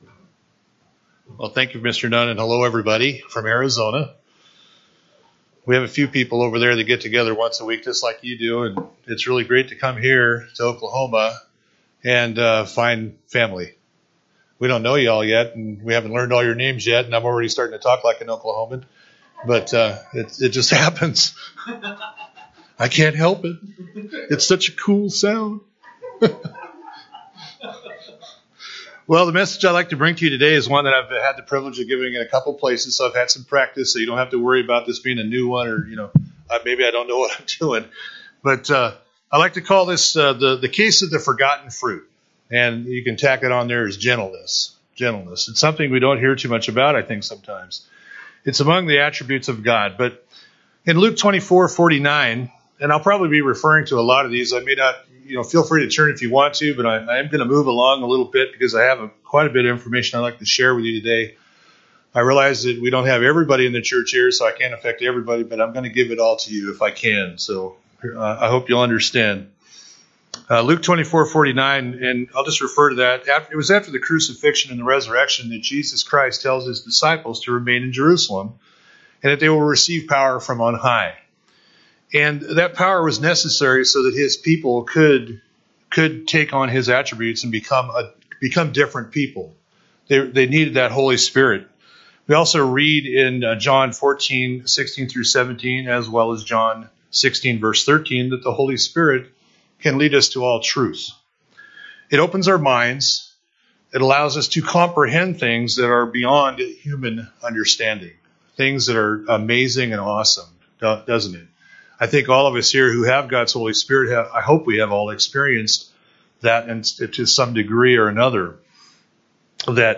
Sermons
Given in Oklahoma City, OK